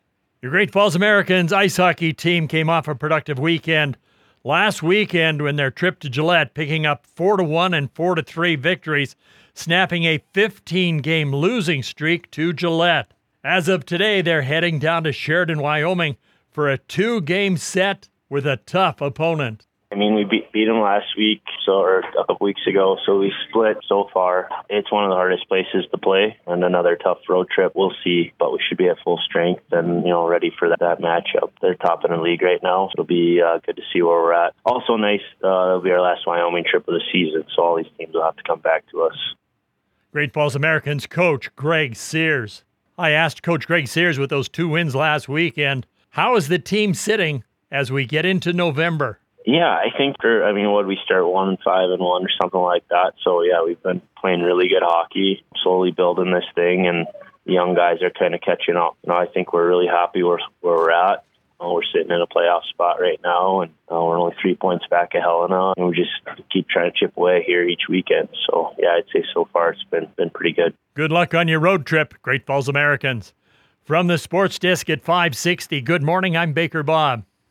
560AM KMON: Weekly Radio Interview